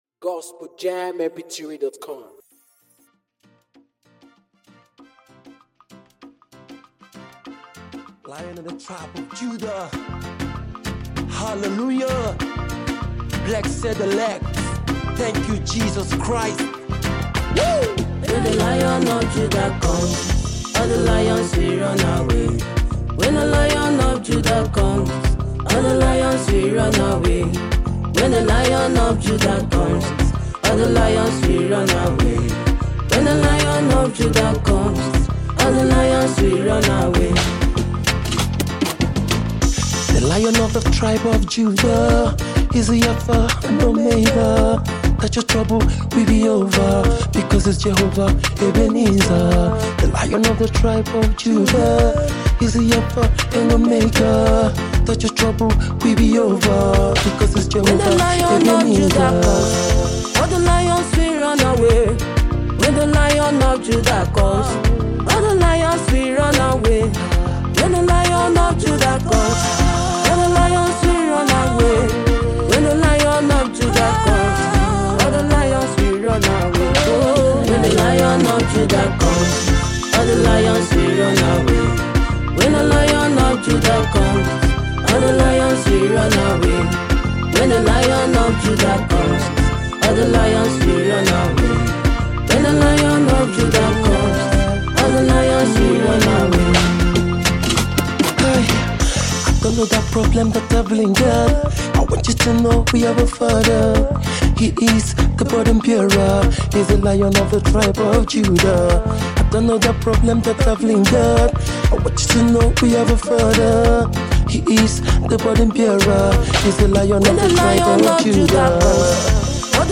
gospel music